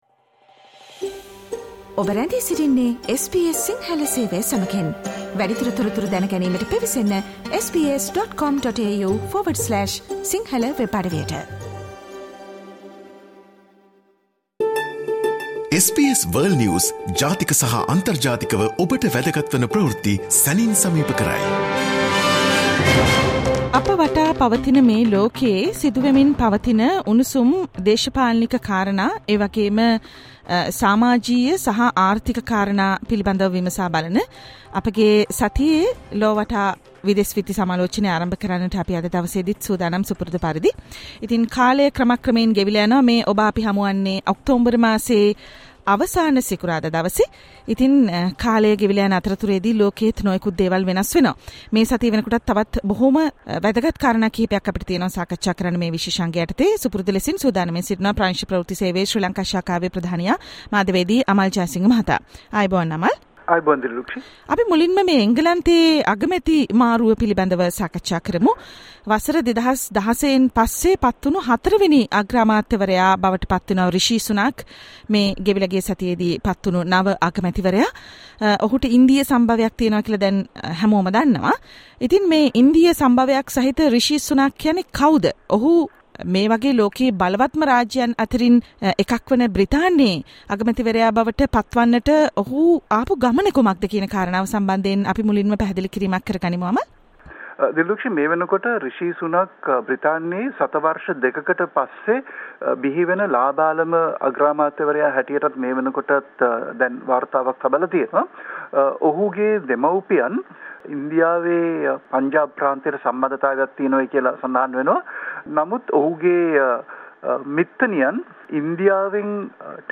SBS Sinhala Radio weekly world News wrap